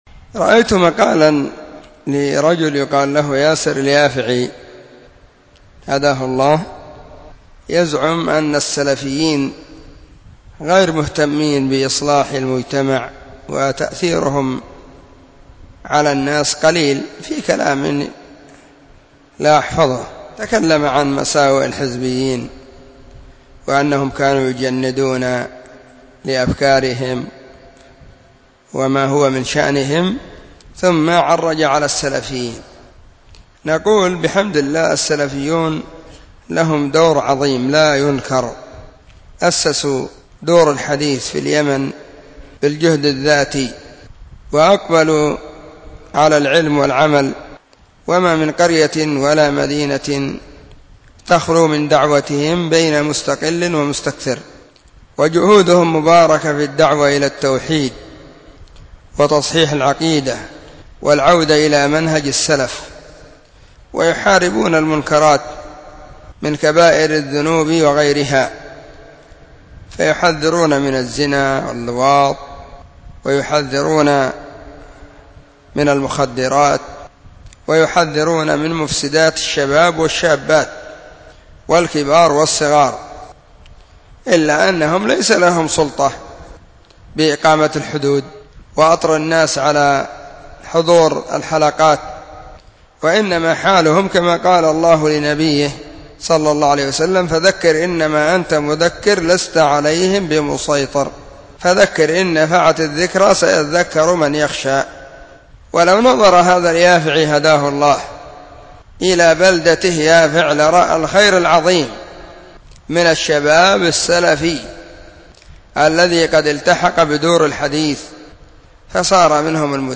📢 مسجد الصحابة – بالغيضة – المهرة – اليمن حرسها الله.
الجمعة 1 صفر 1442 هــــ | الردود الصوتية | شارك بتعليقك